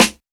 Dilla Snare 09.wav